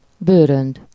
Ääntäminen
US : IPA : [t(ʃ)ɹʌŋk]